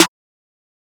MZ Snare [Metro Bape Hi].wav